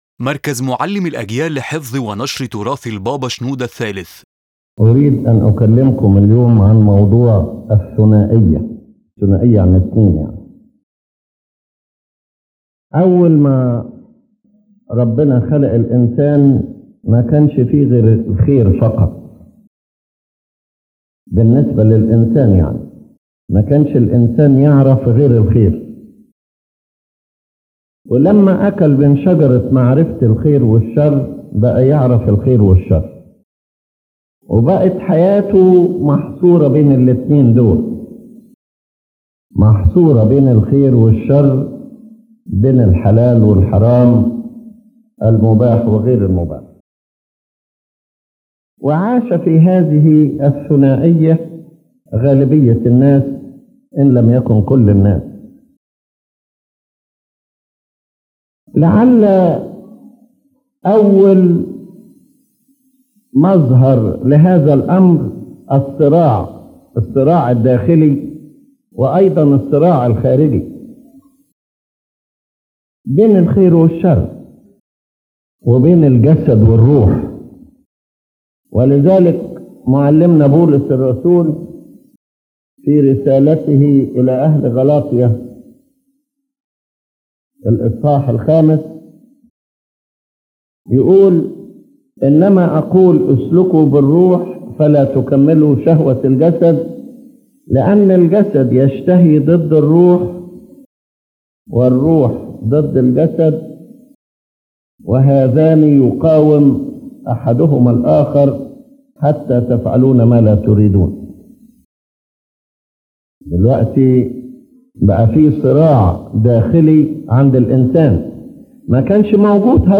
In this profound lecture, His Holiness Pope Shenouda III speaks about the concept of duality in human nature — the inner conflict between good and evil, body and spirit, desire and conscience. He explains that this struggle began after Adam’s fall and that the goal of the spiritual life is to reach inner unity and harmony between thought, heart, conscience, and action.